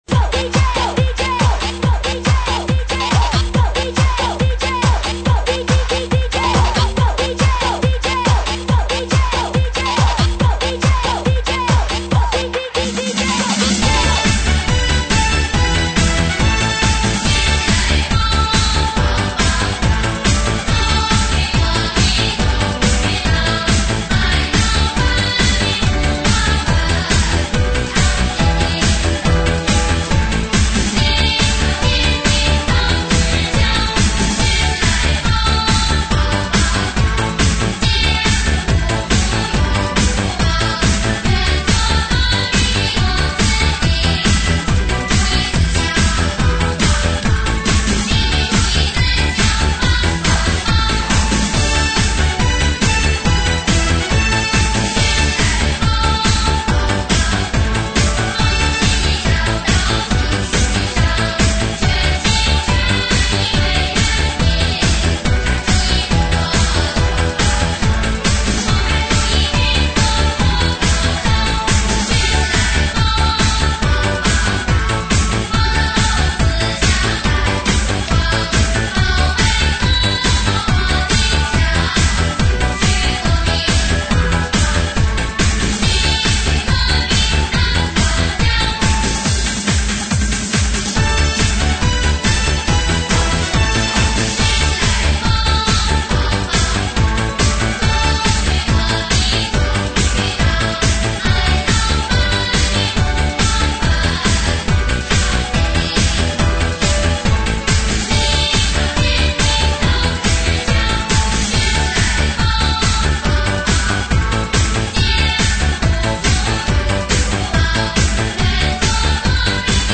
童声合唱及男女声合唱